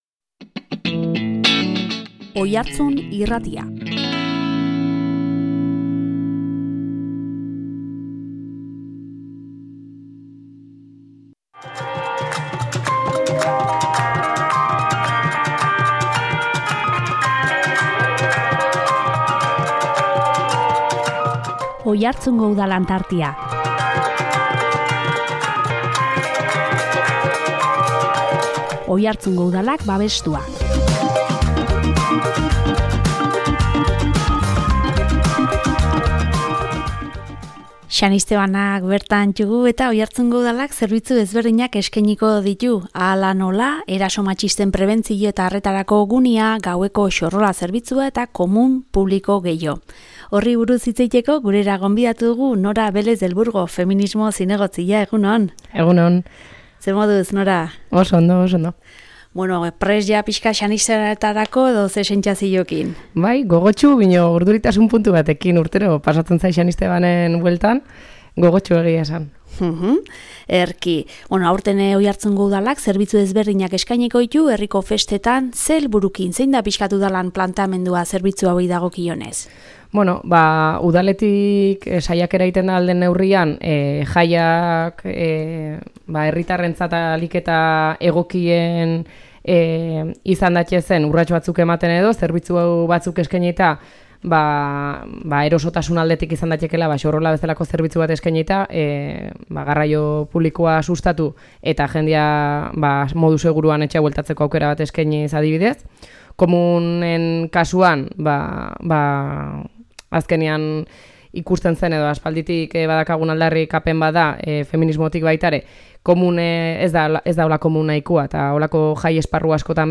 Xanistebanak bertan ditugu eta Oiartzungo Udalak zerbitzu ezberdinak eskainiko ditu, hala nola, eraso matxisten prebentzio eta arretarako gunea, gaueko Xorrola zerbitzua eta komun publiko gehiago. Horri buruz hitz egiteko gurera gonbidatu dugu Nora Velez del Burgo, feminismo zinegotzia.